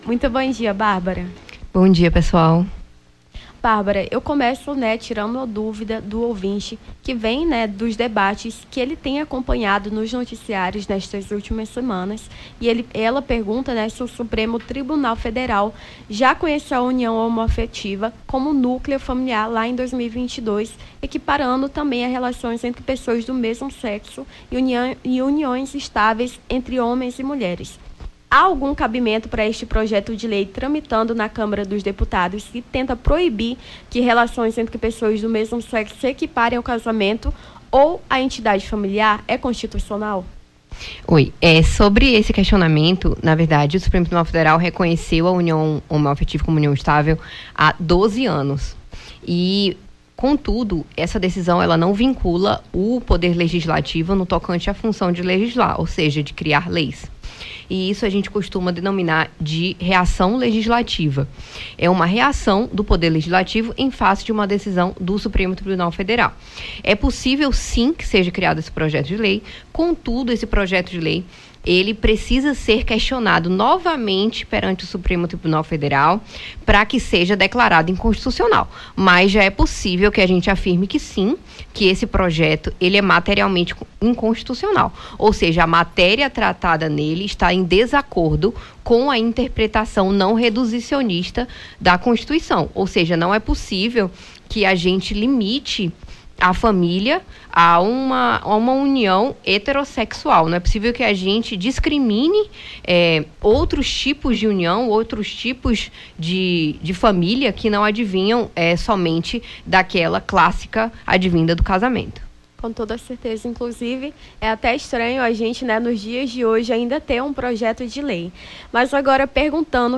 Nome do Artista - CENSURA - ENTREVISTA (DEFENSORIA PUBLICA) 25-09-23.mp3